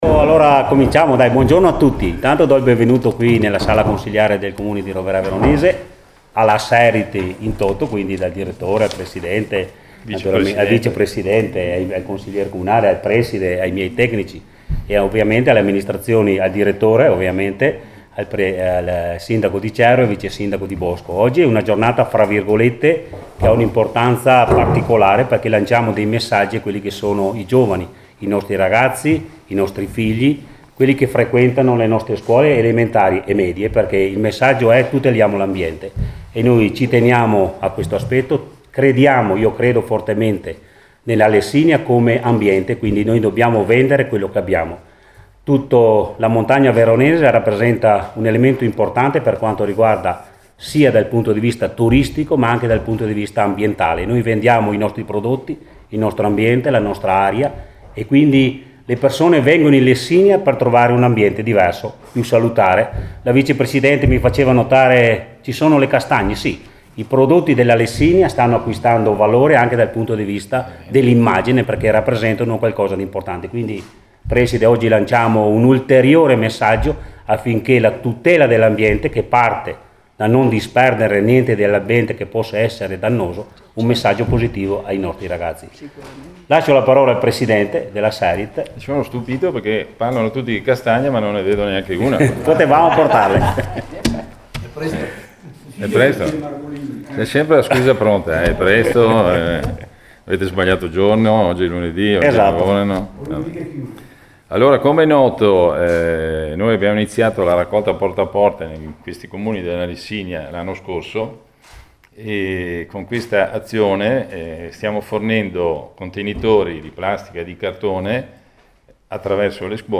(Ascolta la conferenza stampa) – A Roverè Veronese ieri alle 12 si e svolta la conferenza stampa di presentazione del progetto per sviluppare una sensibilità ambientale e mettere in atto comportamenti sostenibili.